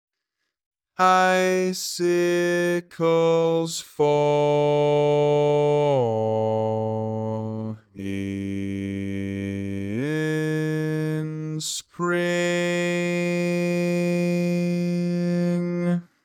Type: Barbershop
Each recording below is single part only.